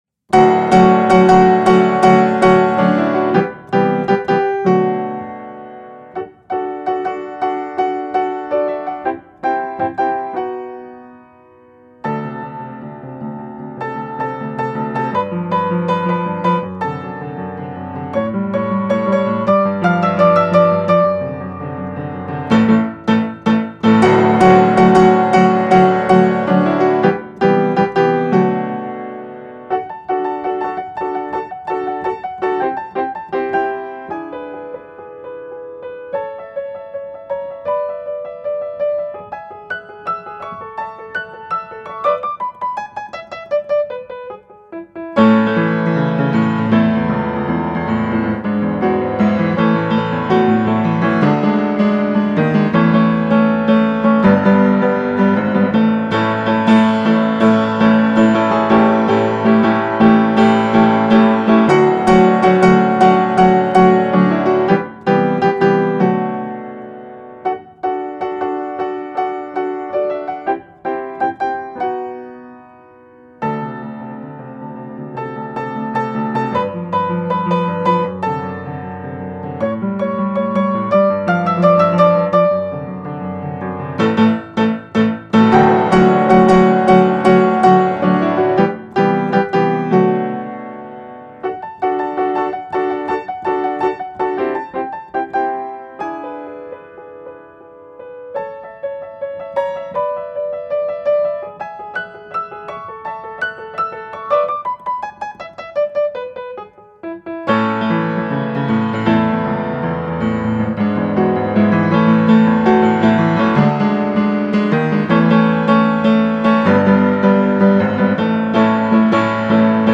Enregistrement de piano en 2024 dans le studio de l'école de musique de la CCMP (01)